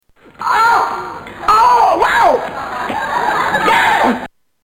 Category: Television   Right: Personal
Tags: Neil - The Young Ones Nigel Planer Neil from The Young Ones The Young Ones The Young Ones clips